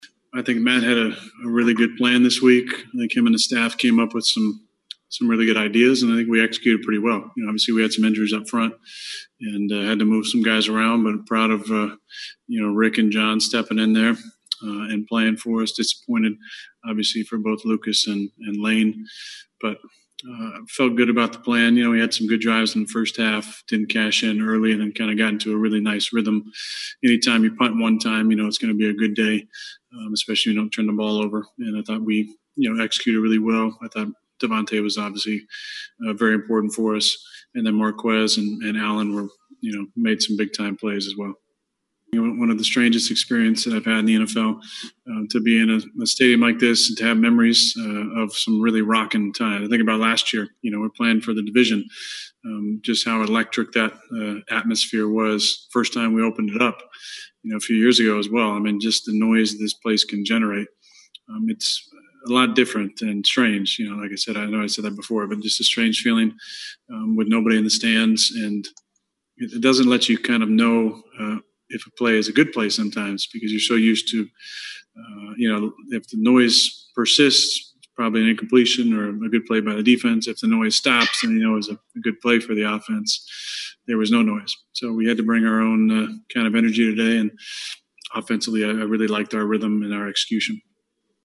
Afterwards, the Packers paraded LaFleur and three players before the zoom camera for post-game press conferences.